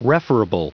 Prononciation du mot referable en anglais (fichier audio)
Prononciation du mot : referable